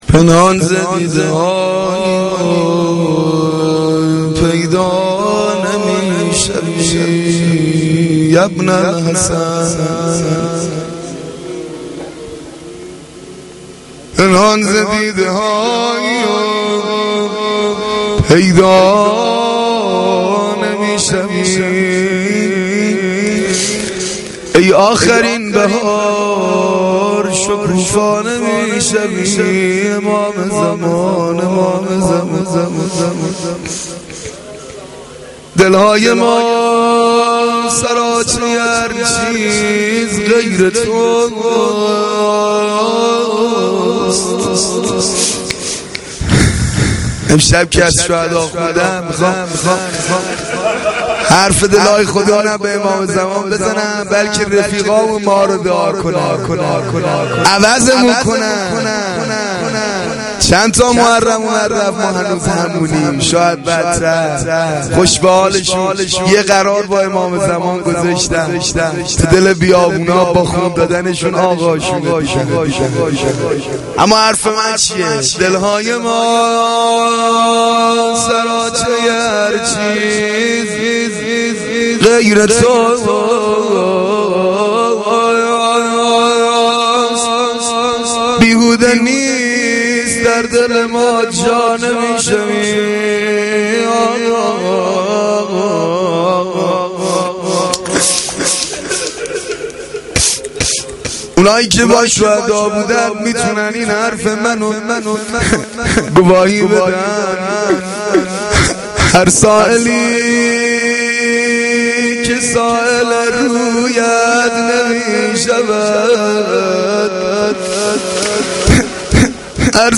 مداحی روضه